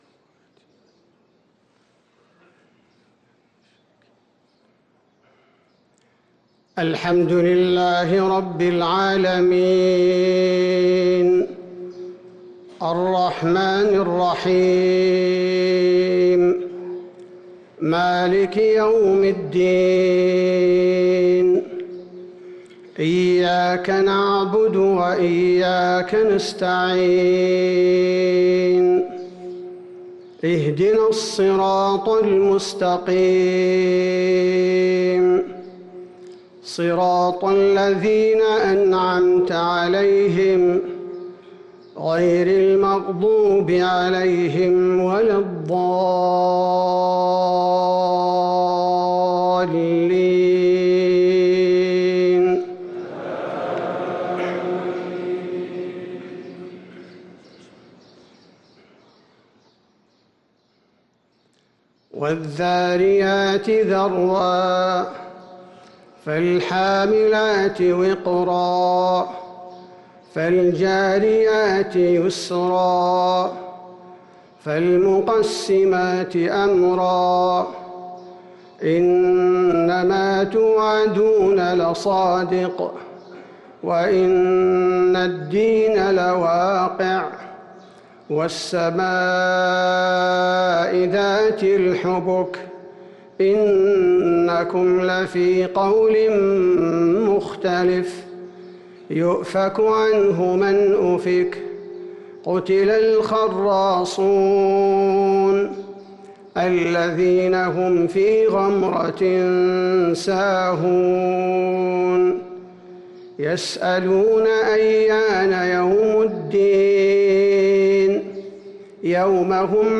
صلاة الفجر للقارئ عبدالباري الثبيتي 9 رمضان 1444 هـ
تِلَاوَات الْحَرَمَيْن .